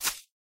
shovel.mp3